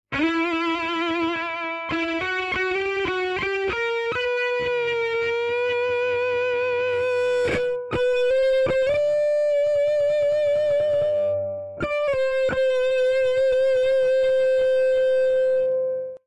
Four independent bands of pure distortion make your tracks scream with delight.
Each sequence is in a dry version and then effected in different ways.
Screamer.mp3